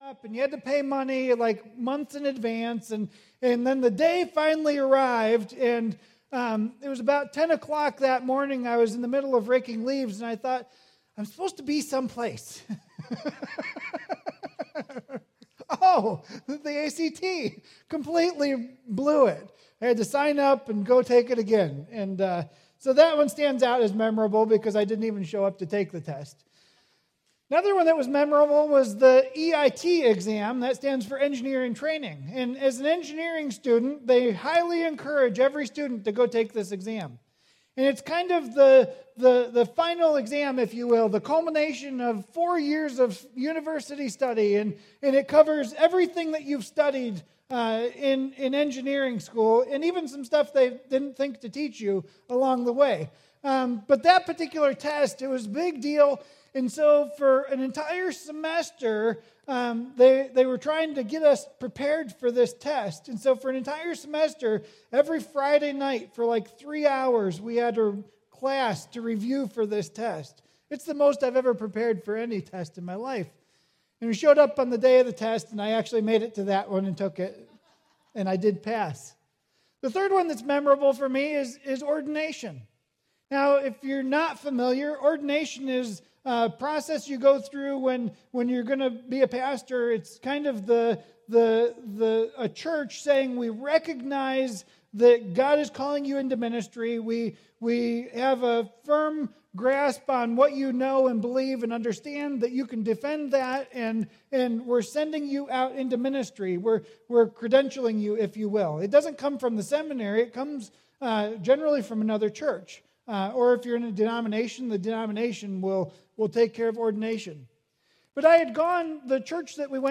Sermons | Grace Fellowship Church